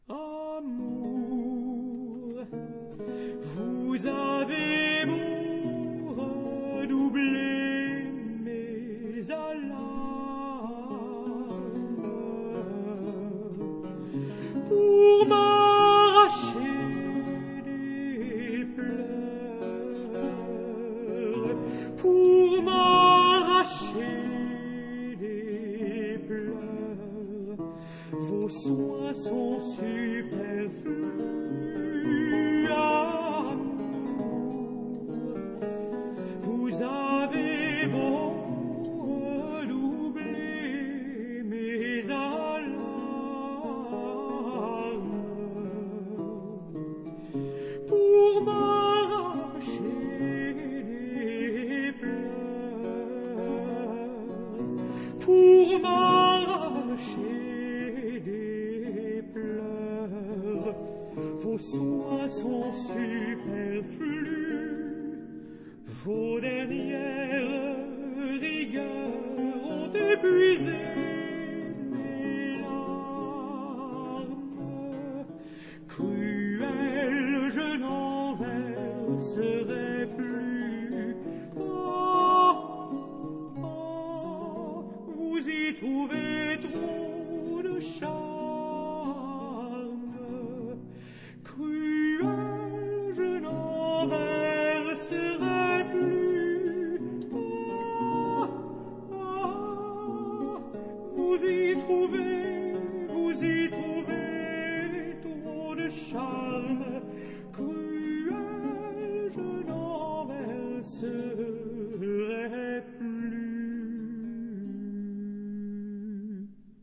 假聲魅力
假聲一點都不曖昧，也不庸俗。
那其中的情感，反而更加鮮明。